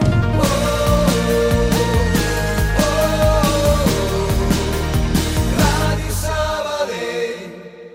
Indicatiu cantat de l'emissora
FM